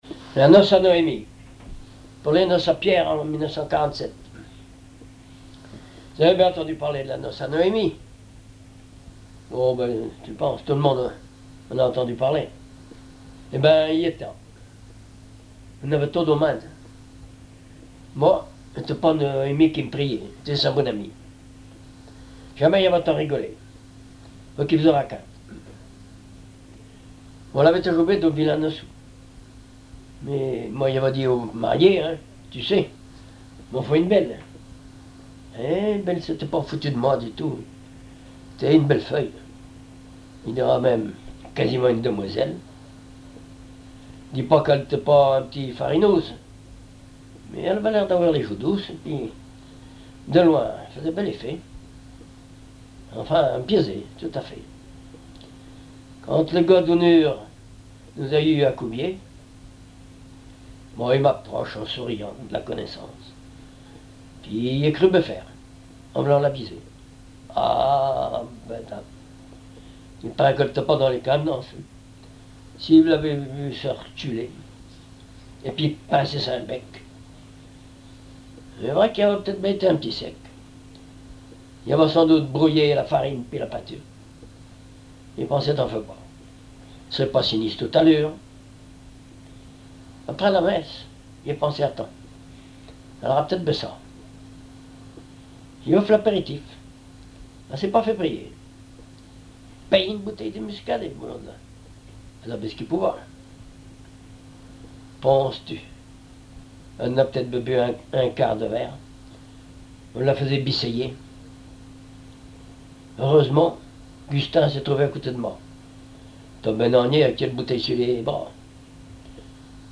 Patois local
Genre sketch
chansons populaires et histoires drôles
Catégorie Récit